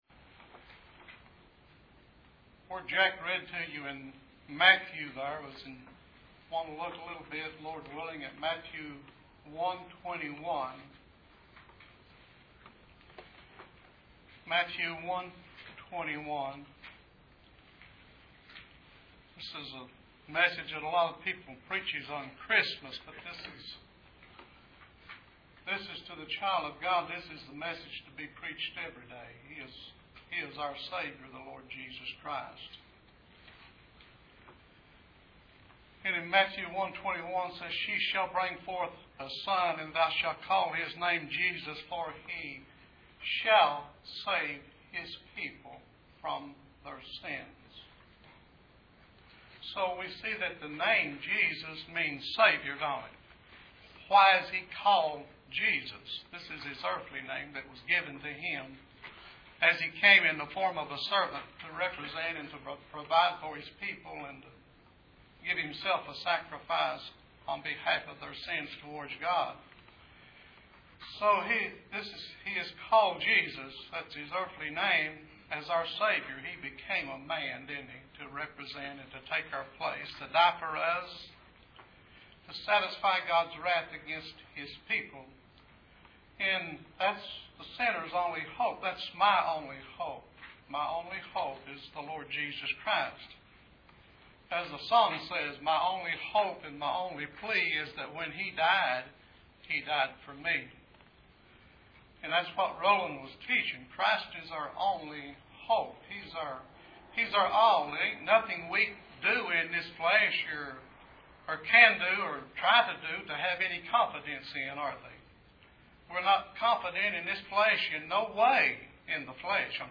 For He Shall Save | SermonAudio Broadcaster is Live View the Live Stream Share this sermon Disabled by adblocker Copy URL Copied!